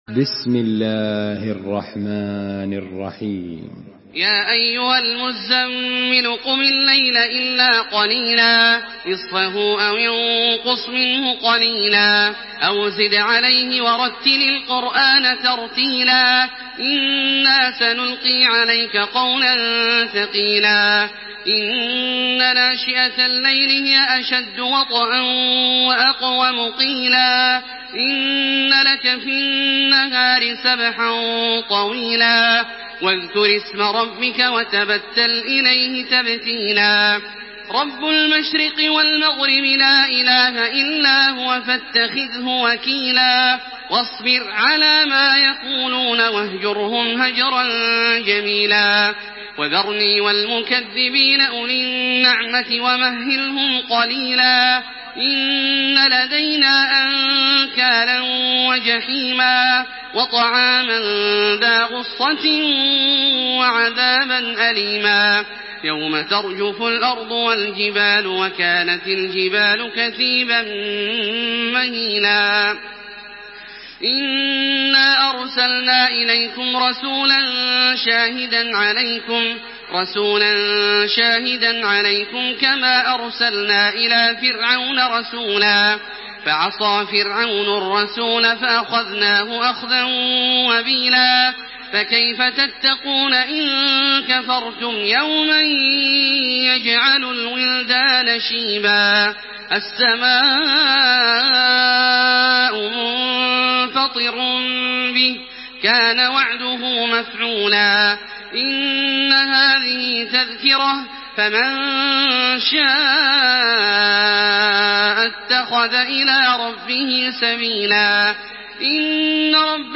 تحميل سورة المزمل بصوت تراويح الحرم المكي 1428
مرتل